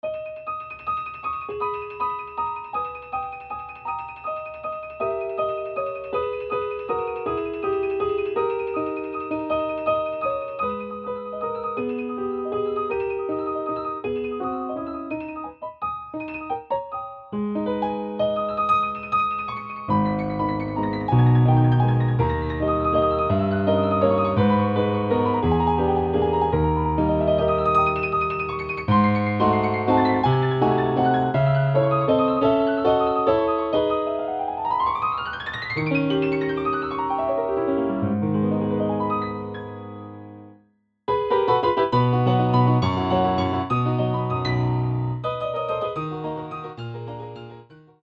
Звучание роялей Shigeru Kawai SK-EX и Kawai EX, воссозданное с помощью технологии формирования звука Harmonic Imaging